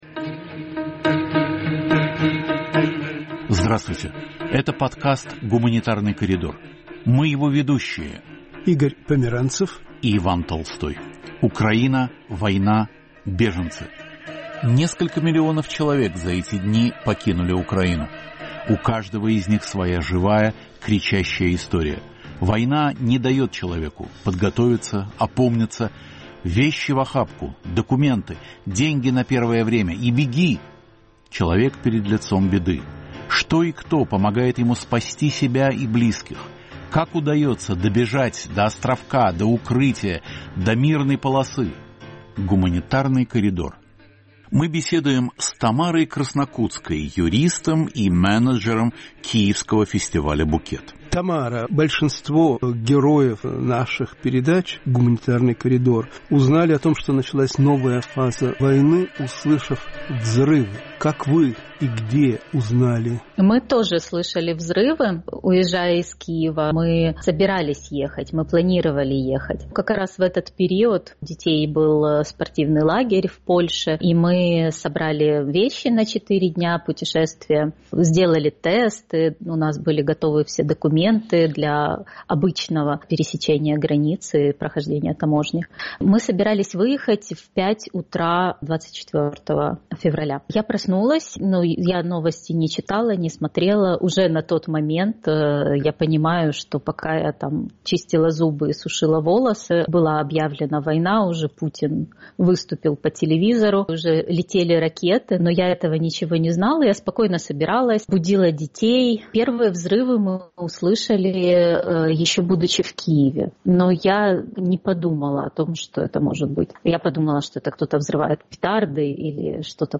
Интервью с украинскими беженцами